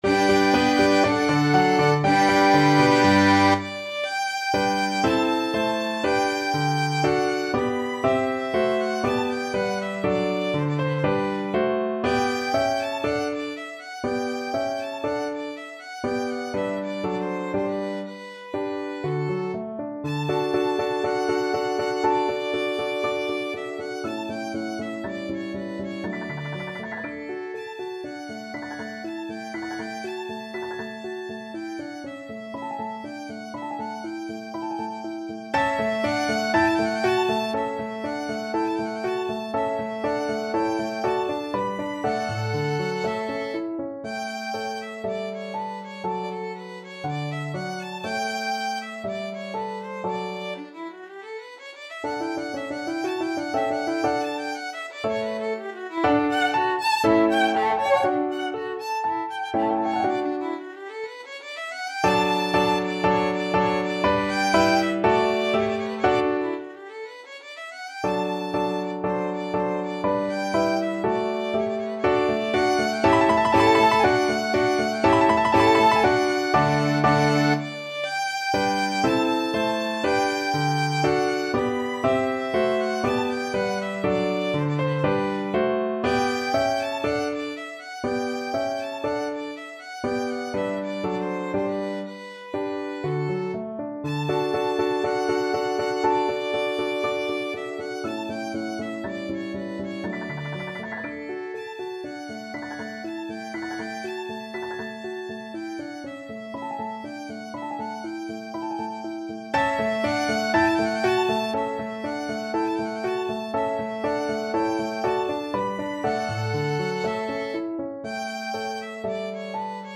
~ = 100 Allegro (View more music marked Allegro)
4/4 (View more 4/4 Music)
Classical (View more Classical Violin Music)